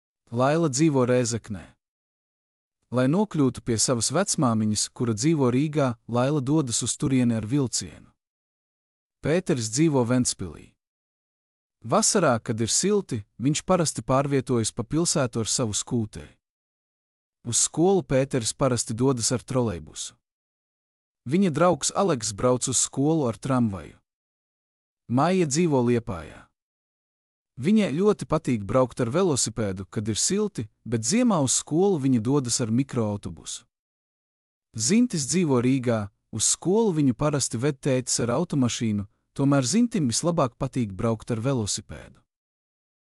Klausīšanās uzdevums.
mp3-output-ttsfreedotcom.mp3